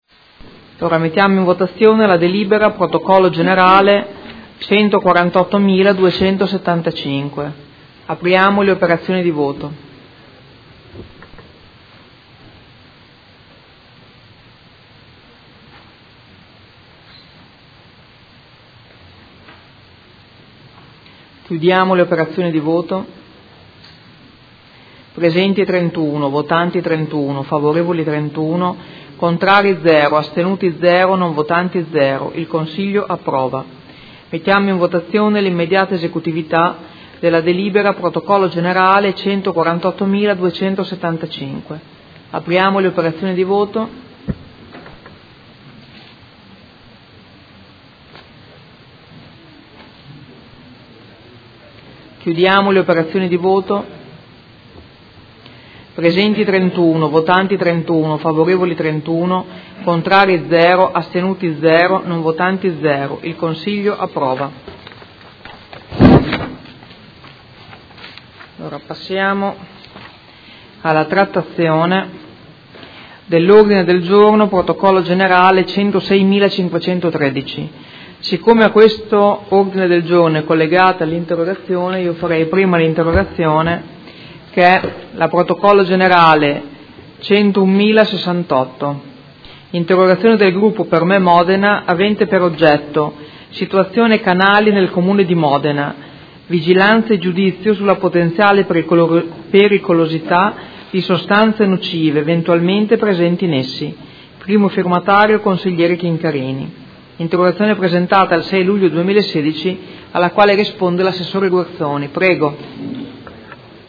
Presidentessa — Sito Audio Consiglio Comunale
Seduta del 27/10/2016 Mette ai voti. Dichiarazione di voto.